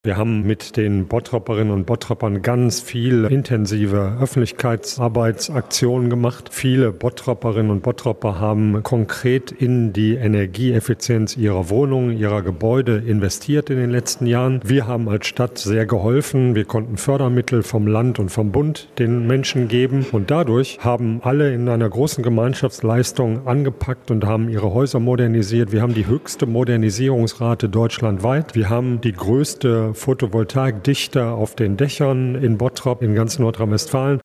Anzeige Bernd Tischler, Oberbürgermeister der Stadt Bottrop Hagen- Eine Stadt auf dem Weg zur Klimafreundlichkeit play_circle Abspielen download Anzeige